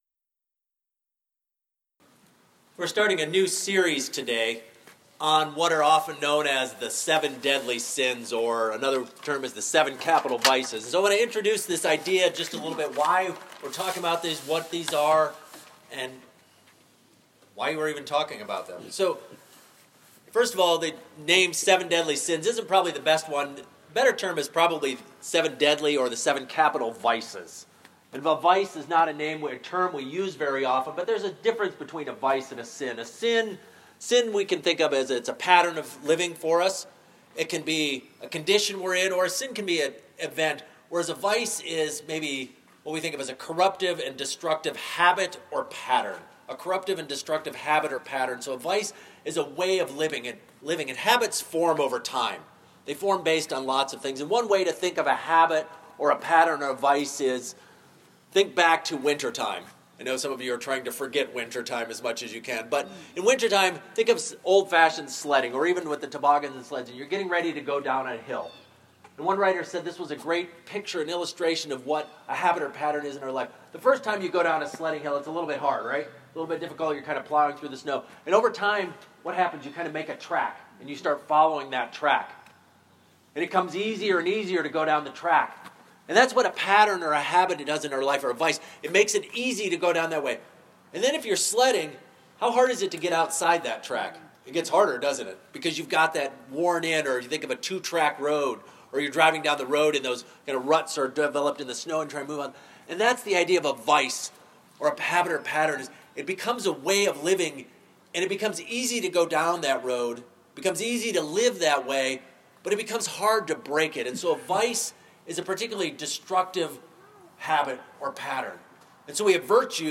Sermons | Fruitland Covenant Church